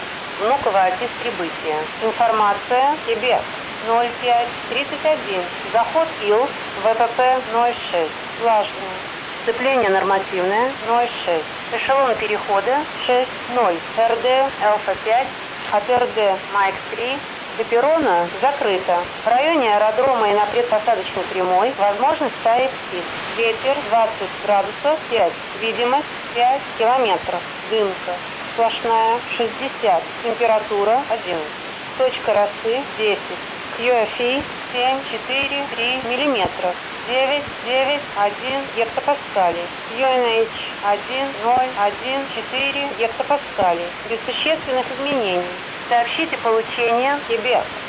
UUWW-АТИС